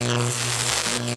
emp-electric-5.ogg